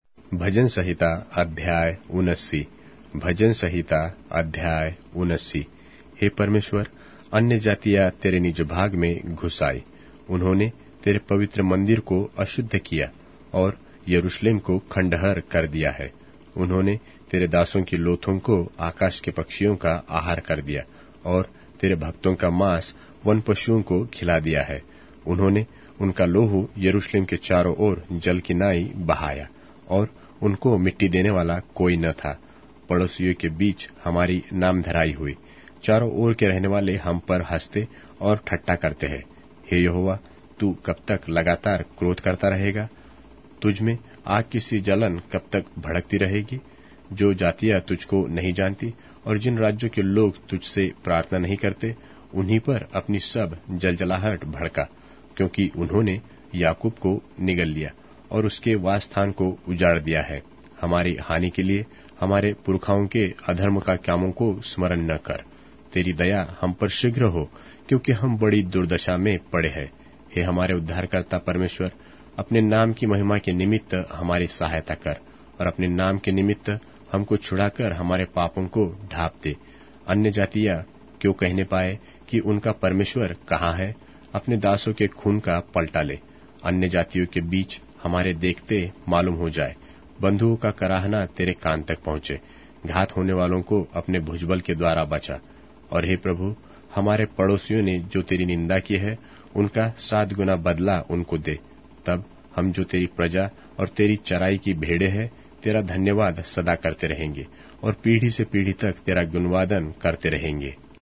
Hindi Audio Bible - Psalms 50 in Mkjv bible version